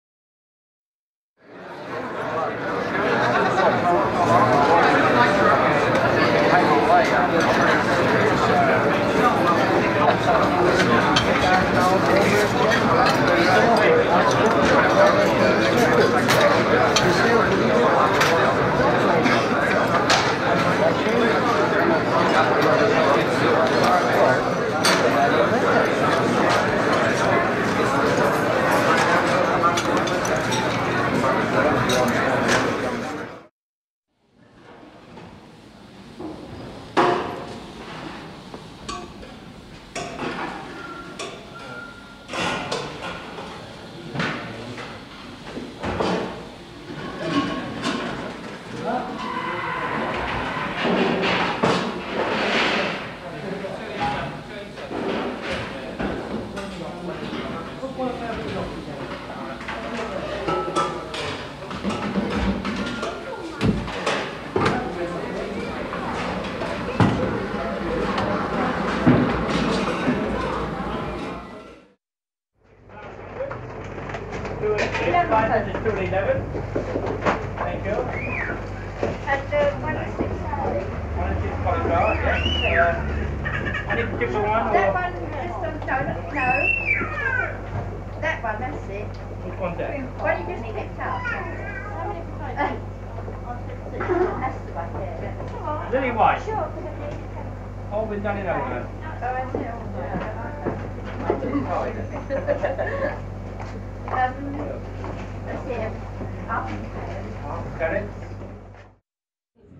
جلوه های صوتی
دانلود صدای جمعیت از ساعد نیوز با لینک مستقیم و کیفیت بالا
برچسب: دانلود آهنگ های افکت صوتی انسان و موجودات زنده دانلود آلبوم صدای همهمه از افکت صوتی انسان و موجودات زنده